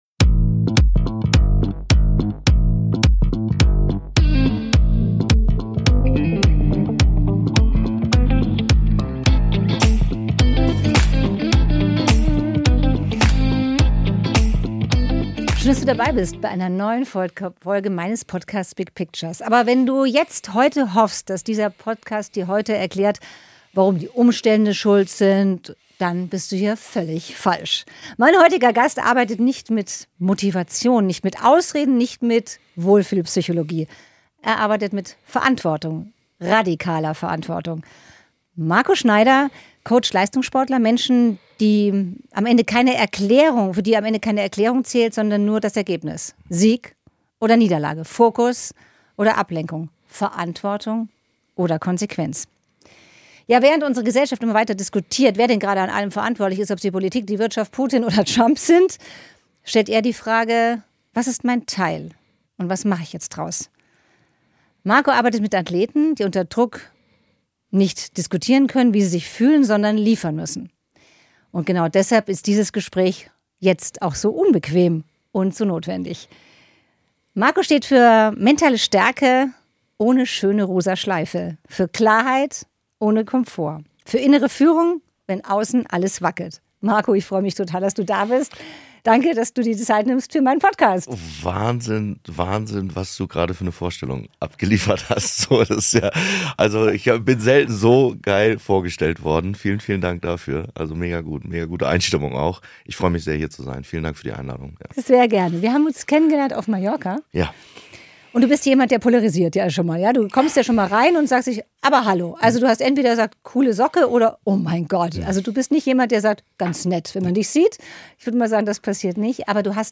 Dieses Gespräch ist unbequem.